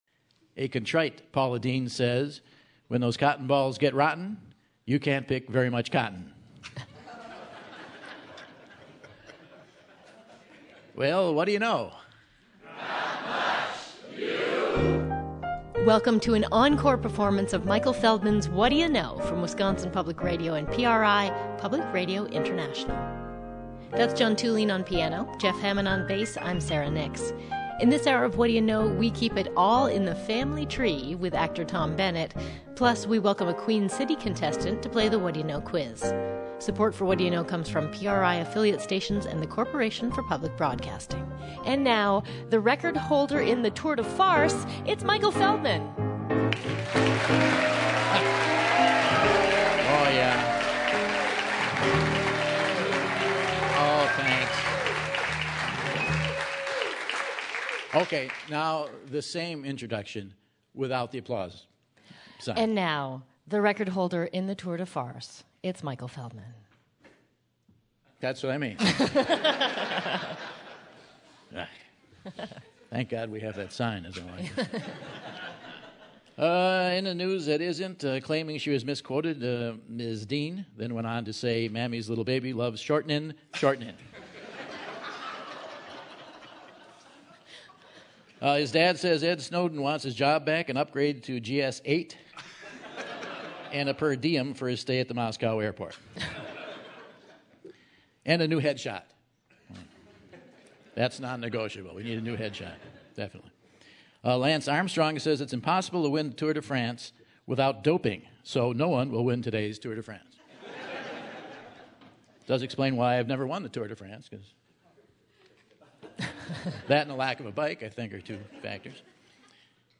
August 9, 2014 - Madison, WI - Monona Terrace - Rebroadcast | Whad'ya Know?